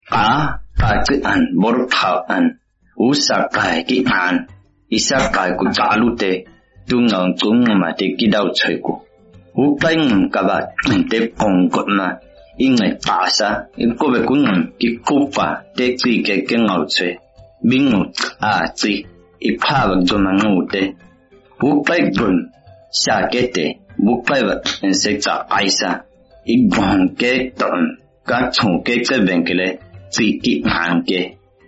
9 February 2020 at 3:38 pm Sounds South African to me with clicks.
I did hear the clicks but I’m not familiar with languages that have them.
It’s clearly Khoi-San, very complex in “click” sounds, perhaps Khoo language.